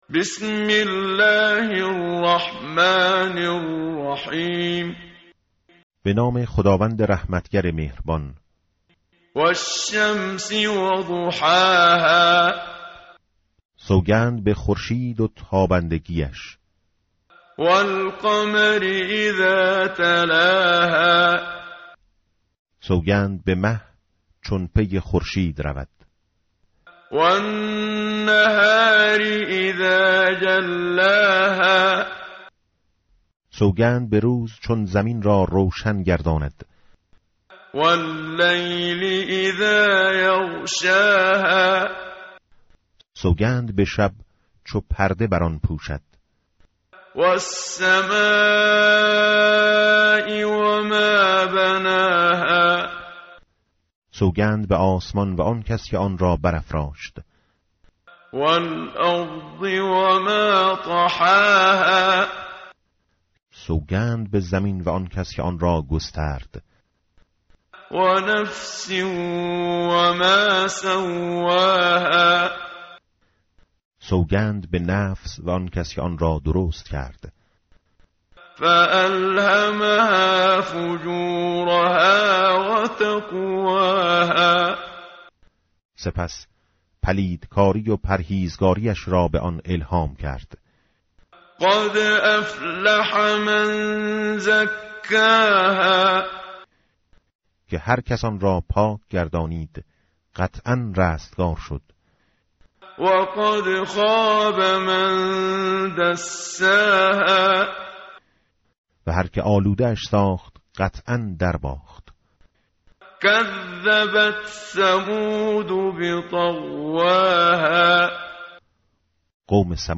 tartil_menshavi va tarjome_Page_595.mp3